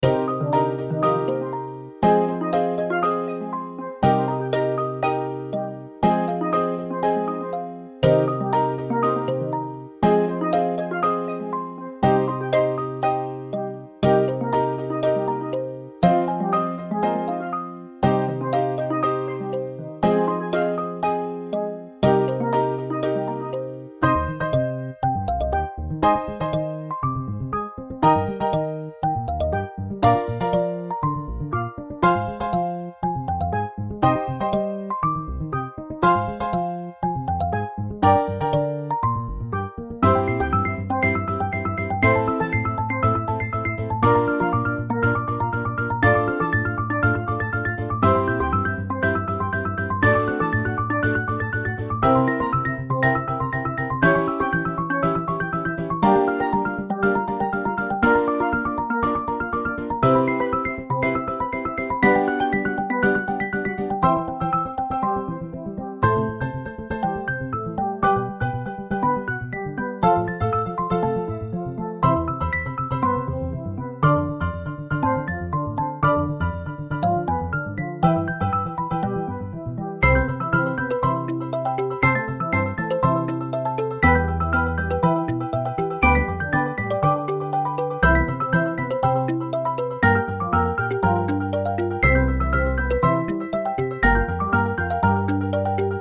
カリンバ、スティールドラム、アコースティックベース、ピアノ